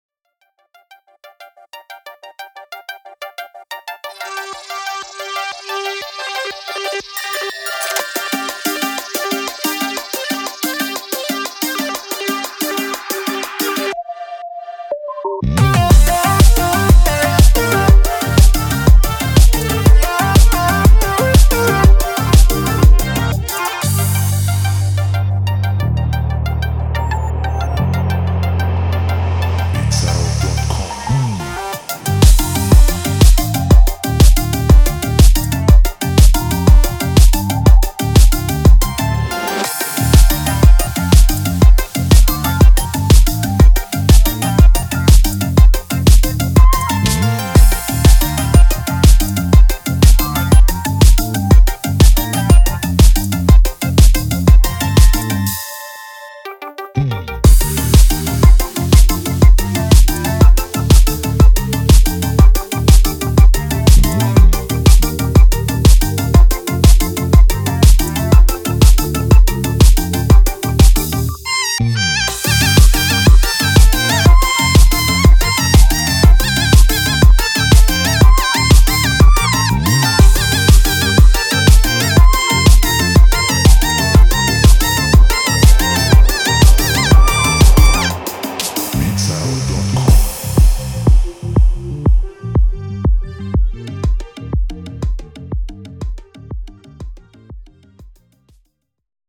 بیت پاپ شاد 6و8 با شعر
دسته و ژانر: پاپ
سبک و استایل:شاد 6و8،عاشقانه
سرعت و تمپو: 91  BPM
تعداد ورس و کوروس: 2 ورس 2 کوروس